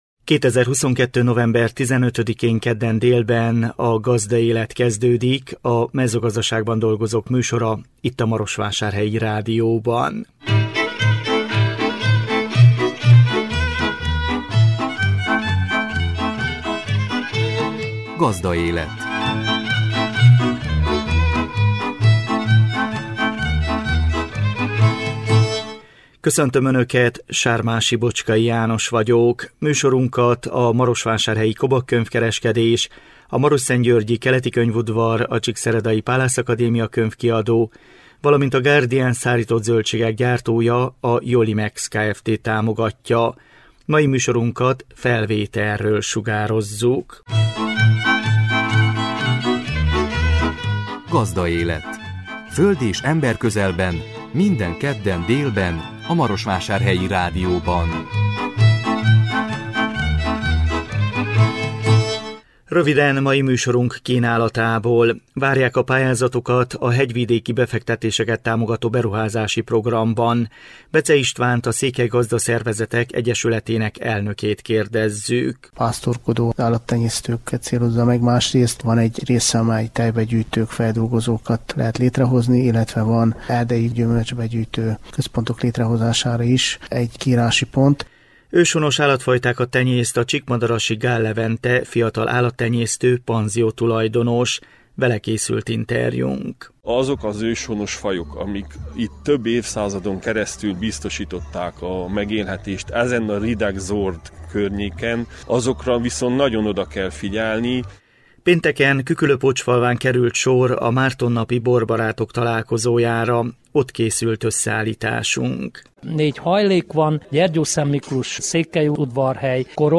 Vele készült interjúnk. Pénteken, Küküllőpócsfalván került sor a Márton napi borbarátok találkozójára. Ott készült összeállításunk.